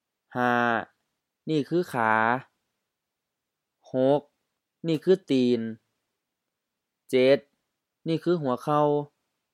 ขา kha: M ขา leg {ขาหน้า = front leg} {ขาหลัง = hind leg} {ส้งขาญาว = long trousers}
ตีน ti:n M ตีน foot
หัวเข่า hu:a-khao M-H หัวเข่า knee